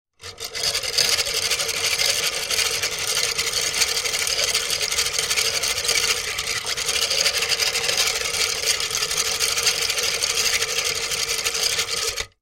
Звук ручного миксера при перемешивании